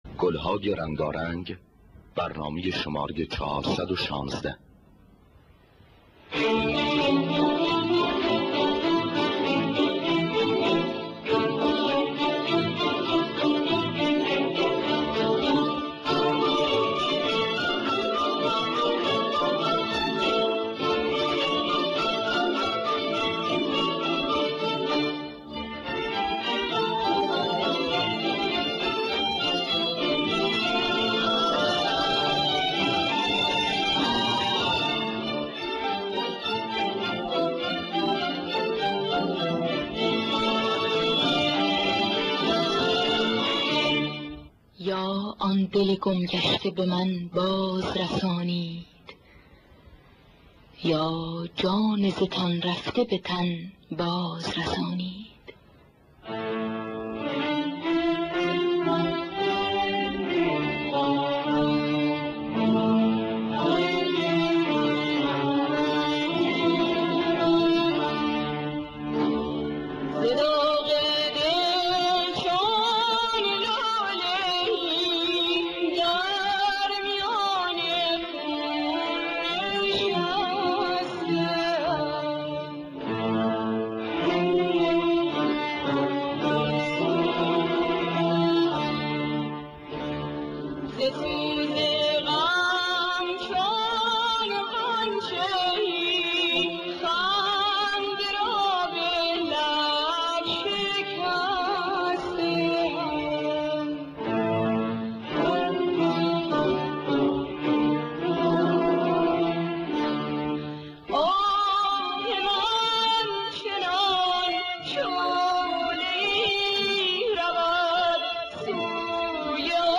گلهای رنگارنگ ۴۱۶ - بیات اصفهان خوانندگان: سیما بینا ایرج نوازندگان: پرویز یاحقی جواد معروفی